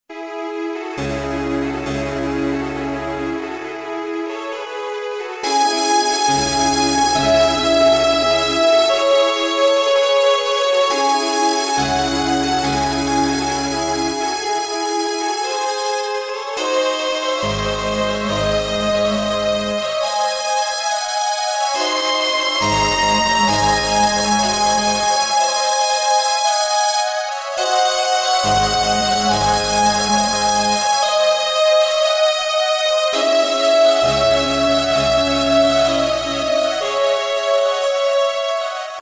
12楽章からなる協奏曲。
クラシック